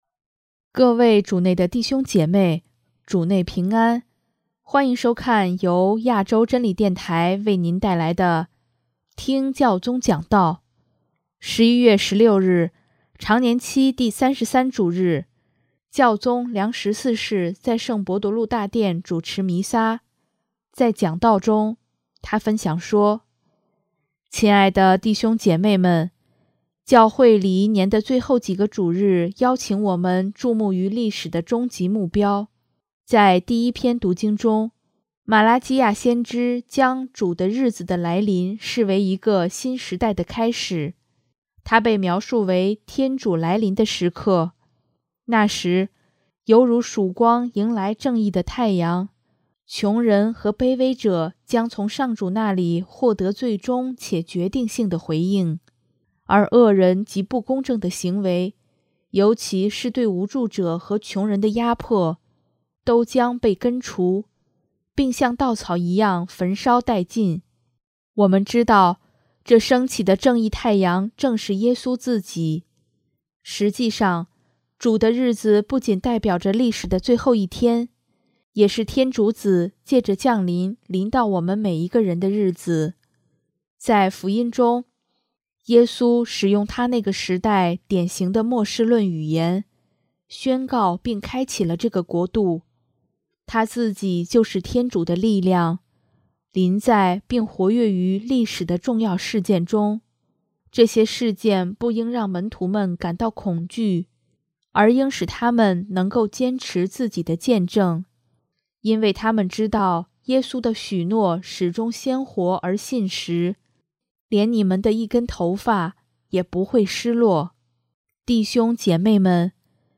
【听教宗讲道】|各国元首应聆听穷人的呼声，没有正义就没有和平
11月16日，常年期第三十三主日，教宗良十四世在圣伯多禄大殿主持弥撒，在讲道中，他分享说：